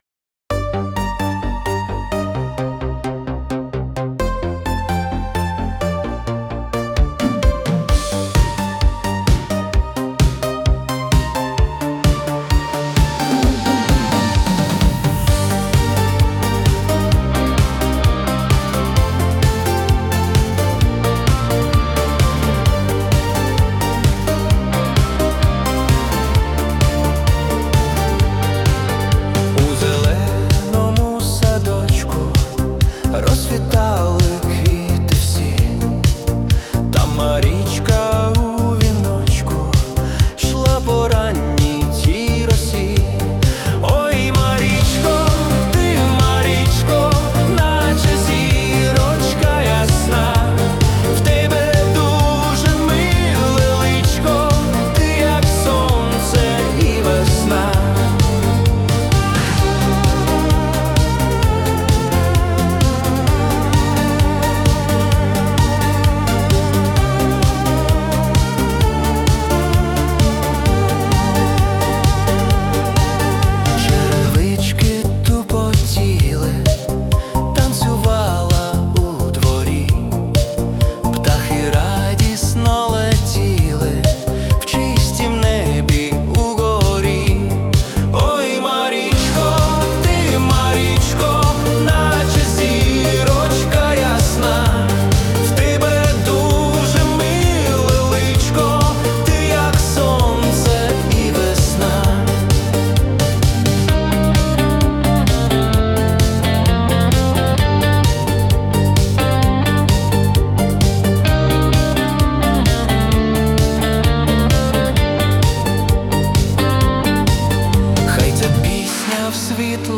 запакований у стильні ритми Italo Disco 80-х років.